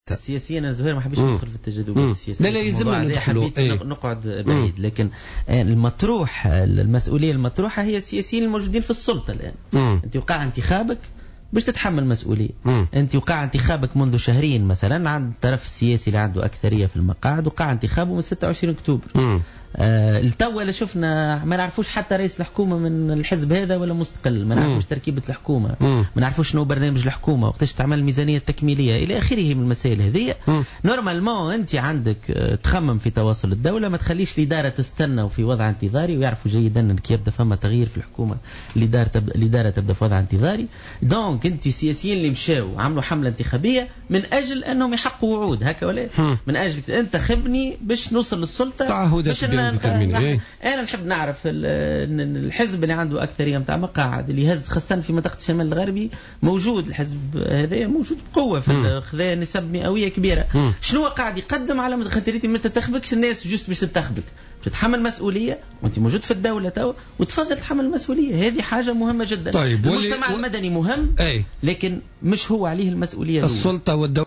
Le dirigeant du parti le Congrès pour la République (CPR), Tarek Kahlaoui, a pointé du doigt la responsabilité du parti Nidaa Tounes lors d’un débat sur le plateau de Jawhara Fm concernant sur le manque de soutien de la population des régions sinistrées Nord-ouest du pays où la vague de froid sévit.